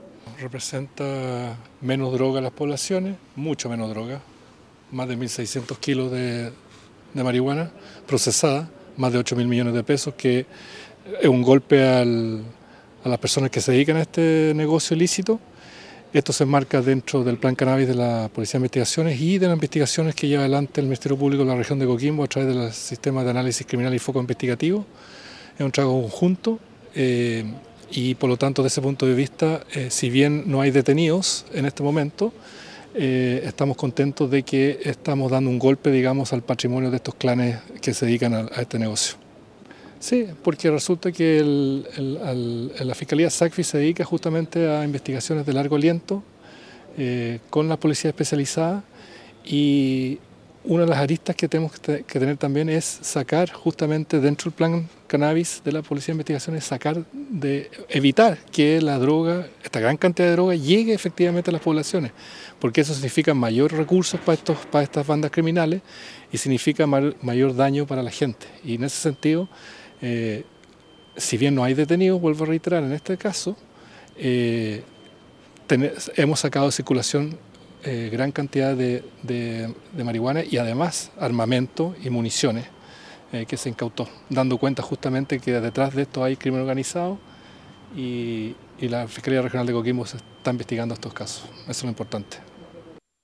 Esta multimillonaria incautación y todas sus evidencias fueron exhibidas a las autoridades y medios de comunicación en el Estadio Municipal Diaguita de Ovalle.
Patricio-Cooper-Fiscal-de-la-Region-de-Coquimbo.mp3